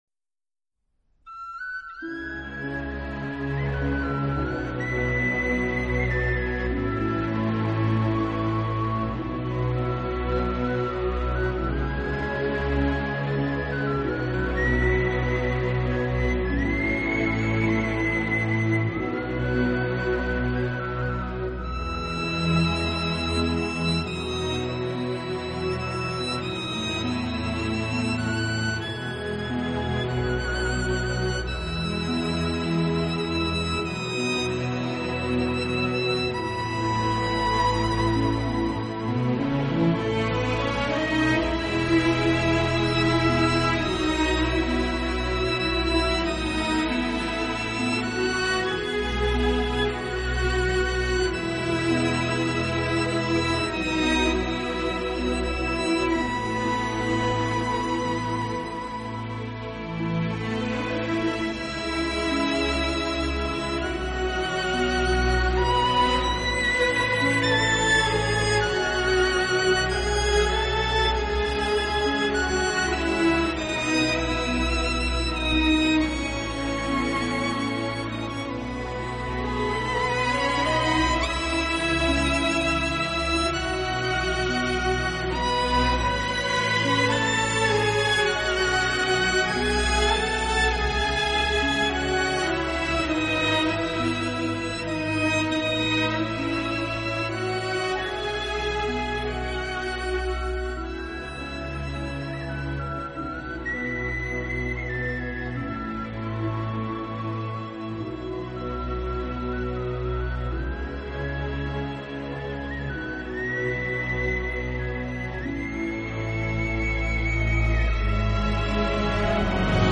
音乐类型：Classical
他和自己的小型管弦乐队默契配合，将古典音乐与大众音乐进行了有机的融合，